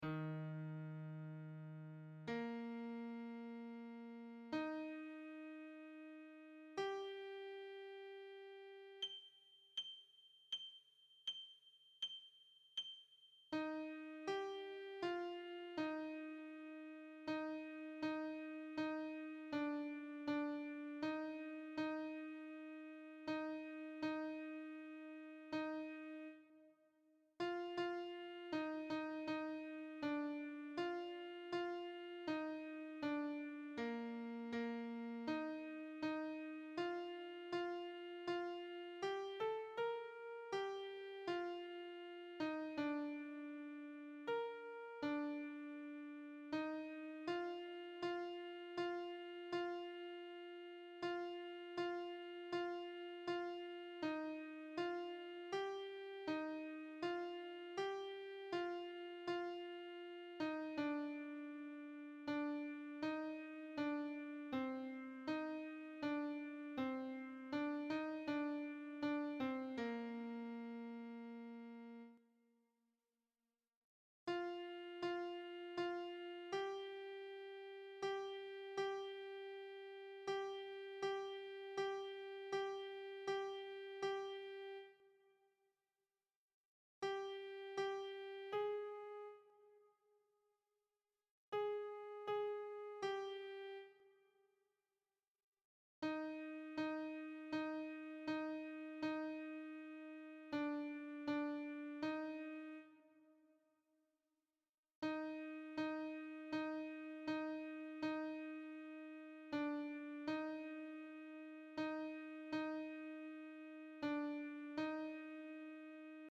Alto - Tenor - Bass
TeachMe_Alto.mp3